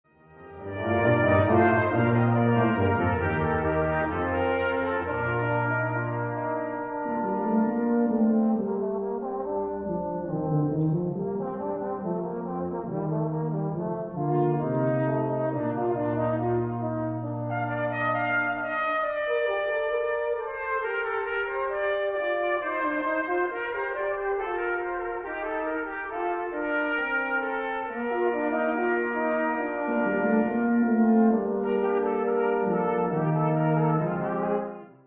Stücke aus unterschiedlichen Konzerten
LIVE